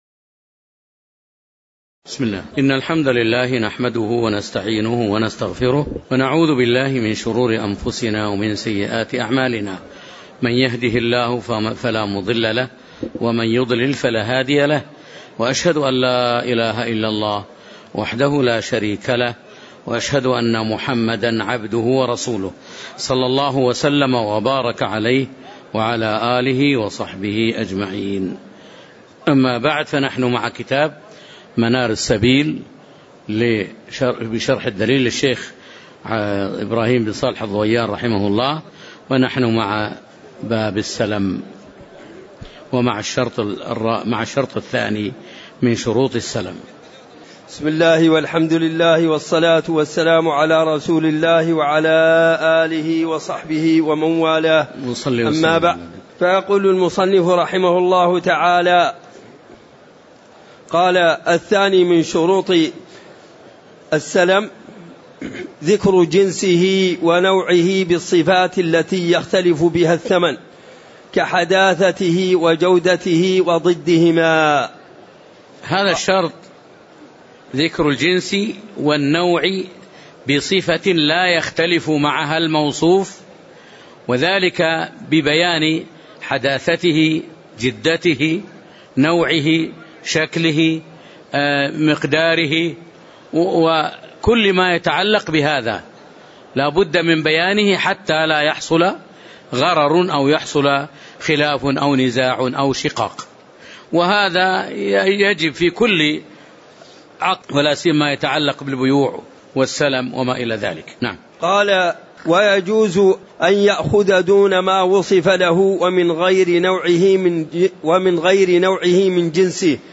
تاريخ النشر ٦ ربيع الثاني ١٤٤٠ هـ المكان: المسجد النبوي الشيخ